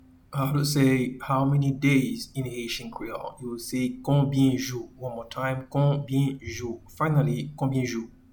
Pronunciation:
How-many-days-in-Haitian-Creole-Konbyen-jou.mp3